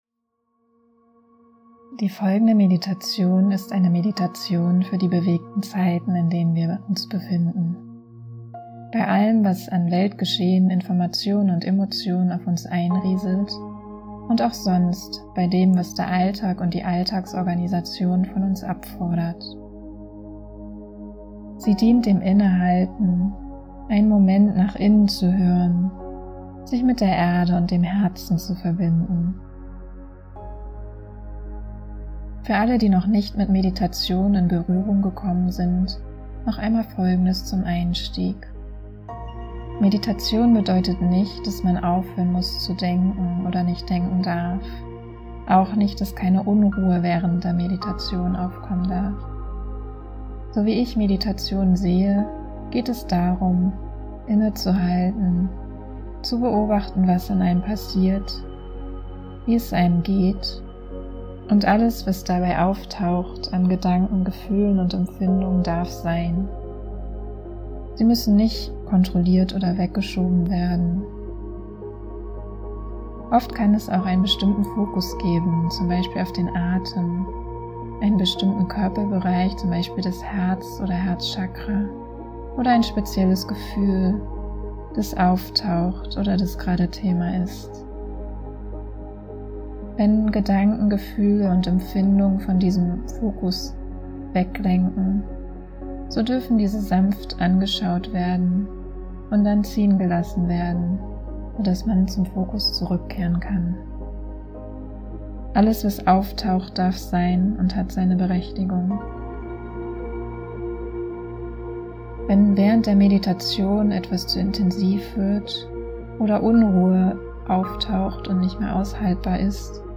Meditation in bewegten Zeiten Kostenfreie Probemeditation für die private Verwendung